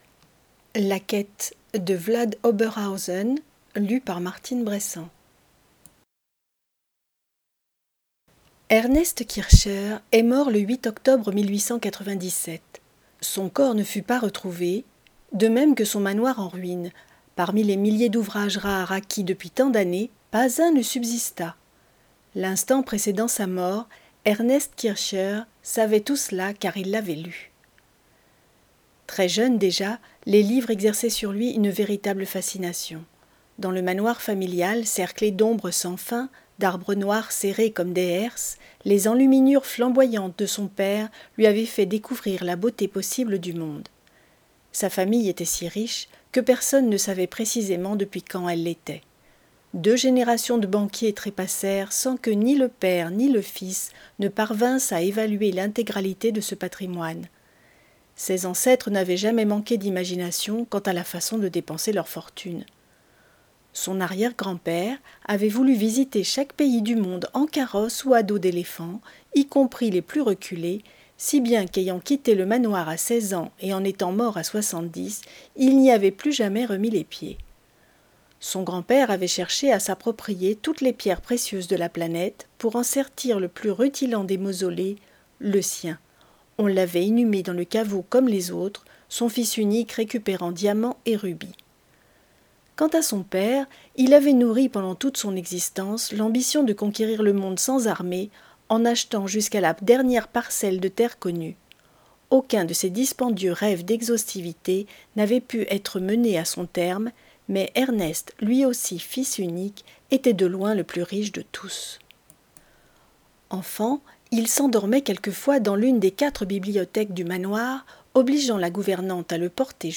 De mes yeux � vos oreilles est un site qui met � disposition des enregistrements audio de textes